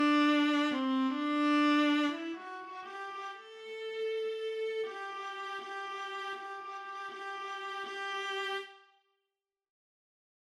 I’ve been working on my string quartet writing, and I discovered a glitch in playback in the viola part. When I start using triplets, the system begins playing each note as an 8th note triplet, even if it’s a quarter note in the triplet or a quarter outside of it!
Something appears to be triggering an unmeasured tremolo sound, but from the little snippet of a picture you’ve posted, I can’t tell what that might be.